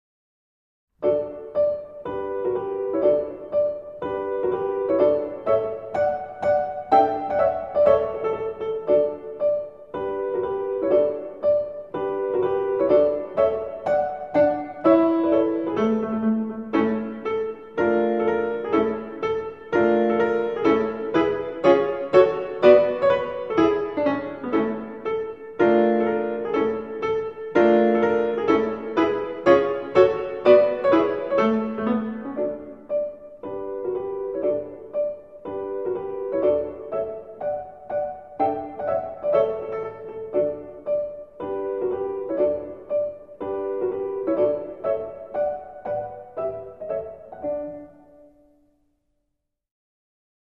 П.Чайковский "Детский альбом", Марш деревянных солдатиков.mp3